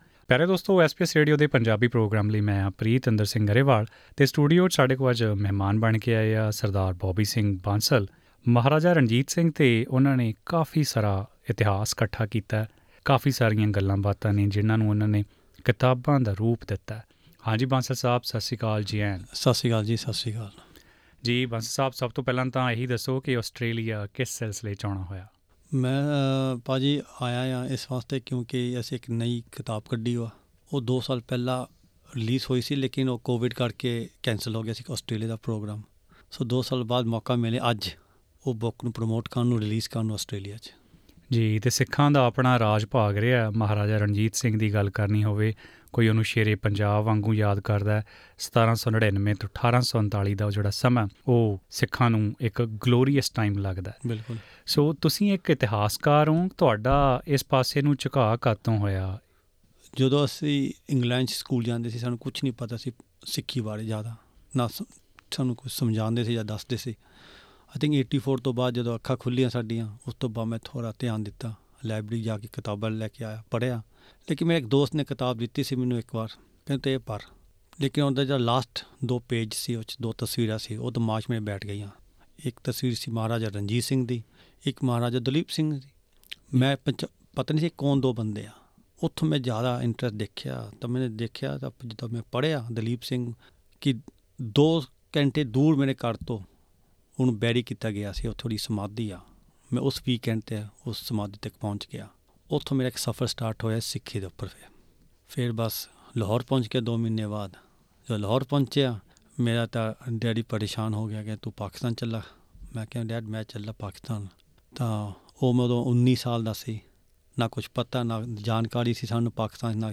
ਇੰਟਰਵਿਊ